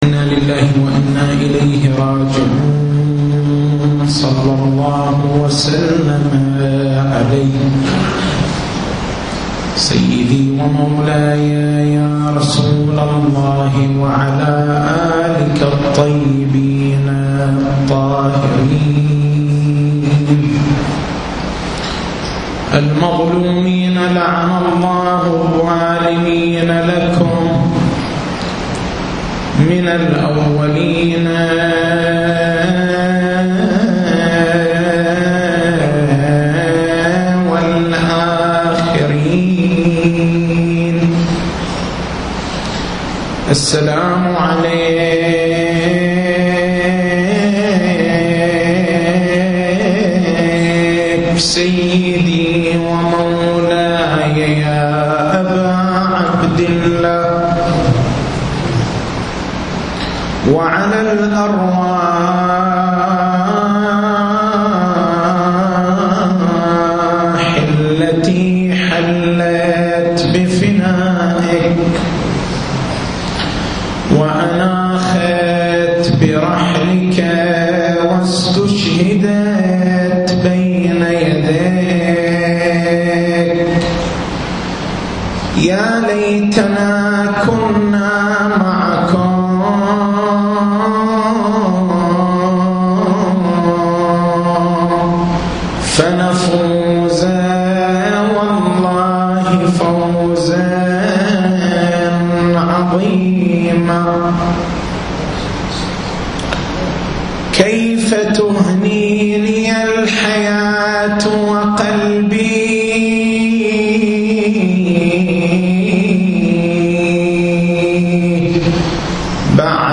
تاريخ المحاضرة: 08/09/1430 نقاط البحث: ما هو معنى مفردة (الثقلين)؟